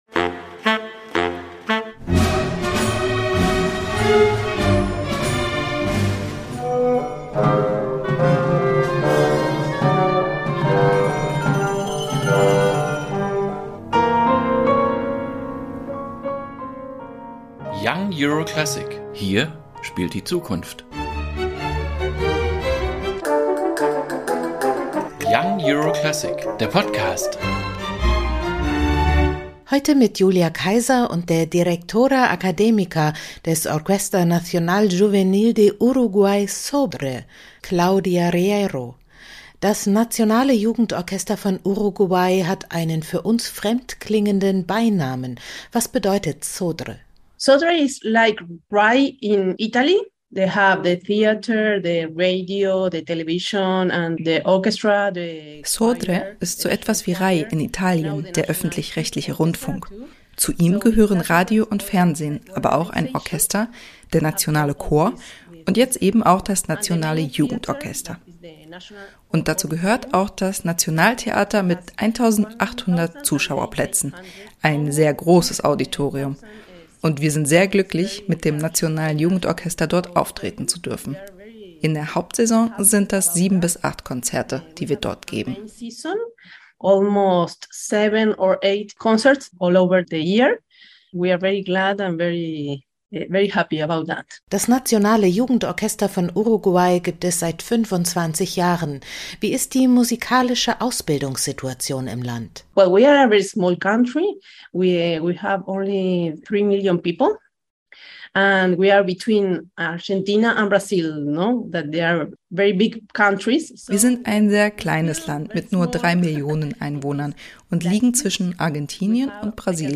Konzerteinführung 19.08.2022 | Orquesta Nacional Juvenil de Uruguay-Sodre